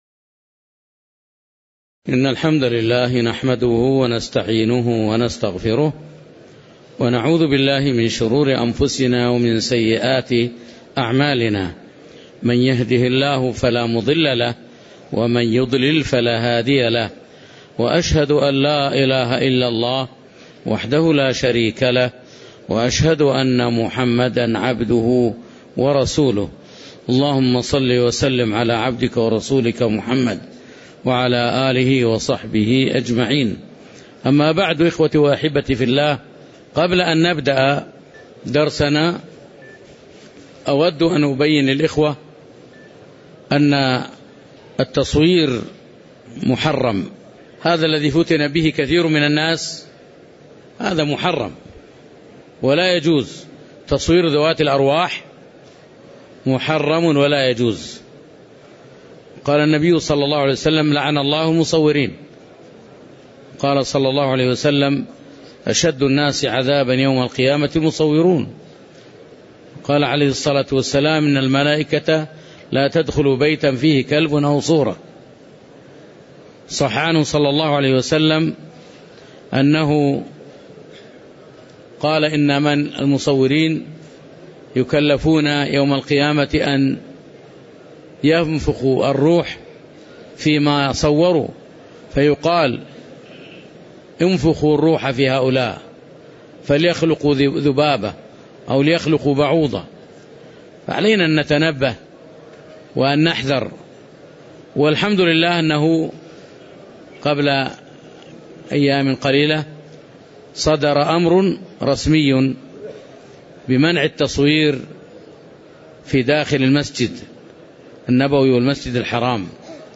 تاريخ النشر ٢ ربيع الثاني ١٤٣٩ هـ المكان: المسجد النبوي الشيخ